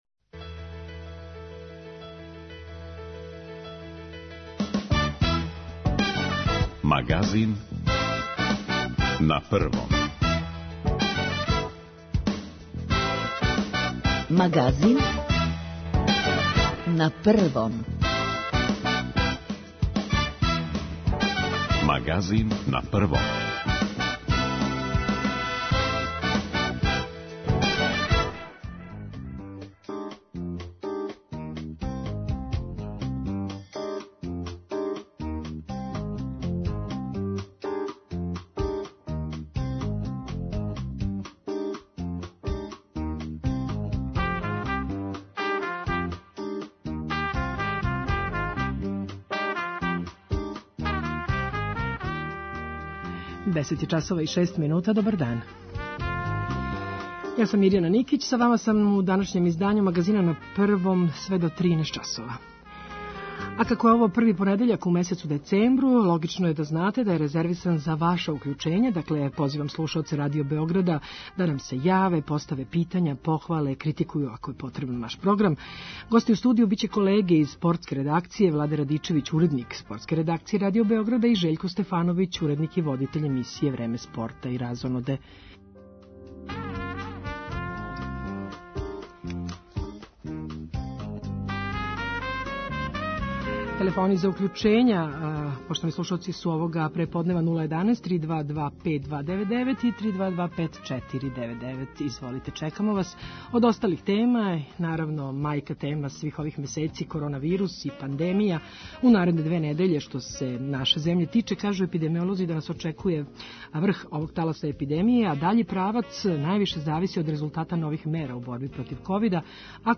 Овај понедељак резервисан је за укључења слушалаца Радио Београда 1, које позивамо да нам се јаве и поставе питања, похвале или критикују наш програм.